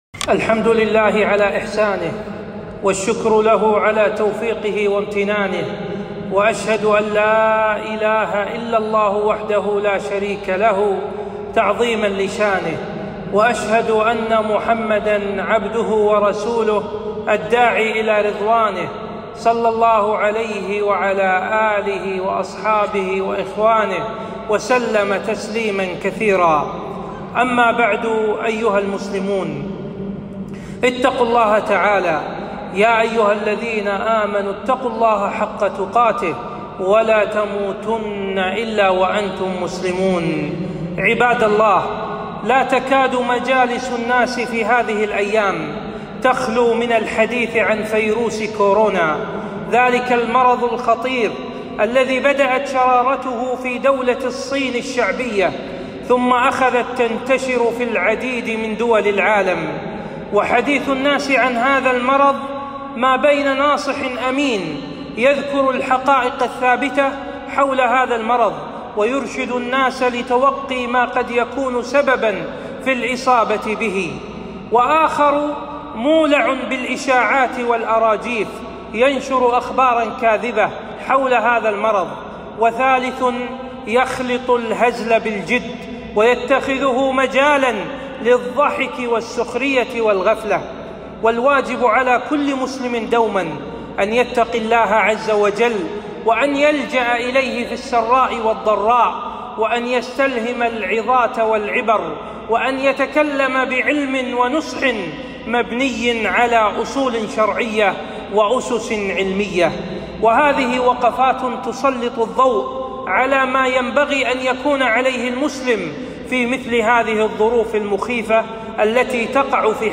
خطبة - وقفات شرعية مع فيروس كورونا